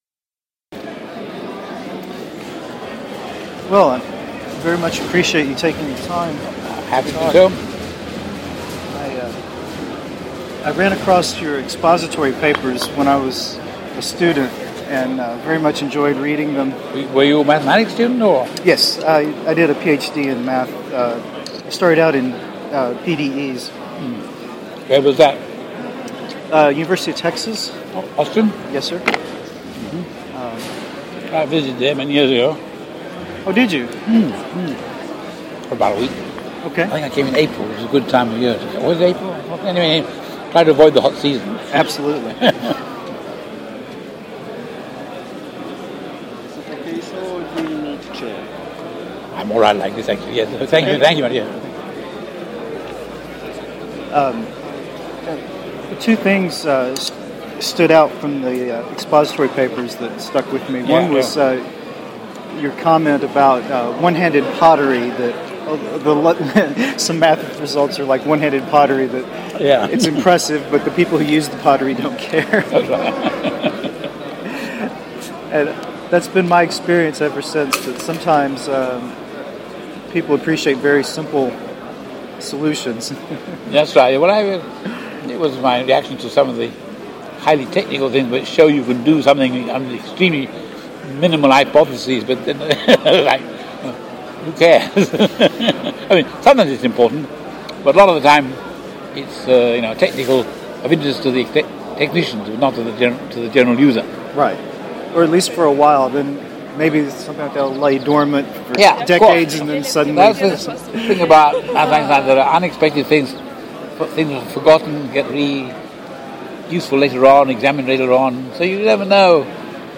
Audio from interview with Sir Michael Atiyah
Audio of Atiyah interview